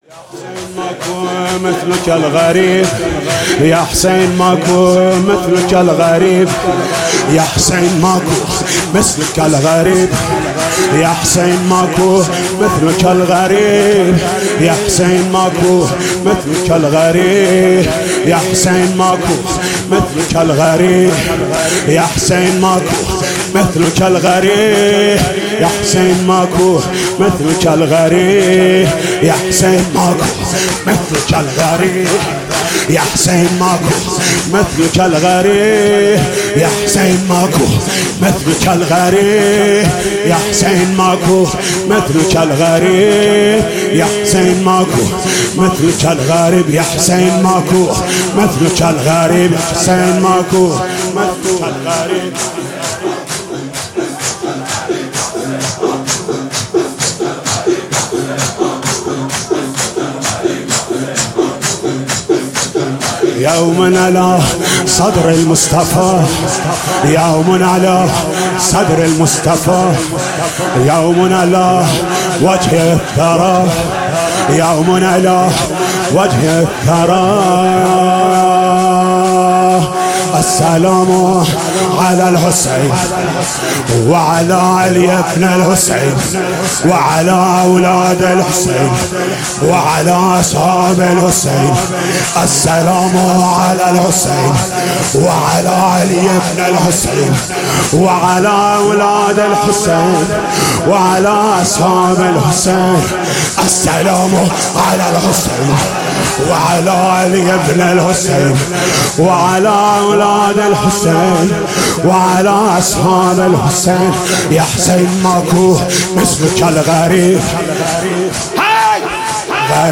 شب_چهارم_محرم باصدای حاج محمود کریمی -(یاحسین ماکو مثلک الغریب)